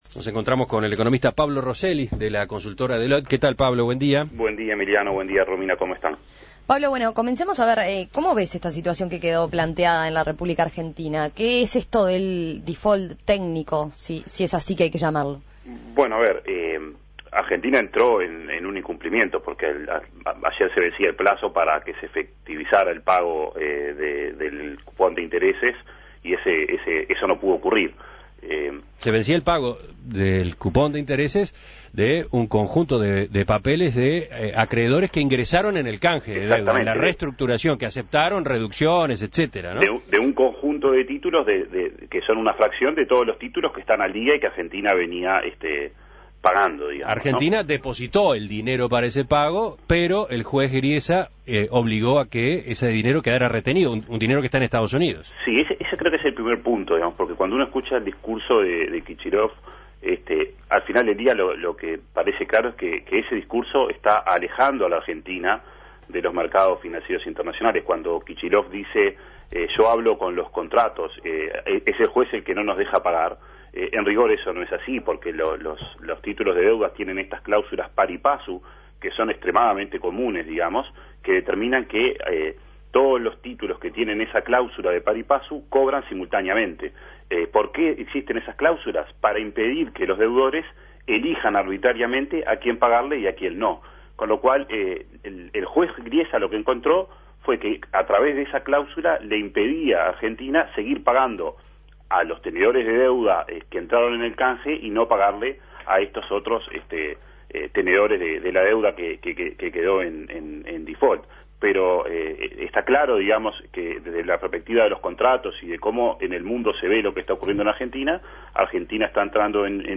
(emitido a las 7.53 hs.)